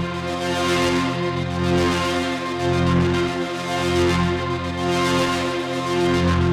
Index of /musicradar/dystopian-drone-samples/Tempo Loops/110bpm
DD_TempoDroneB_110-F.wav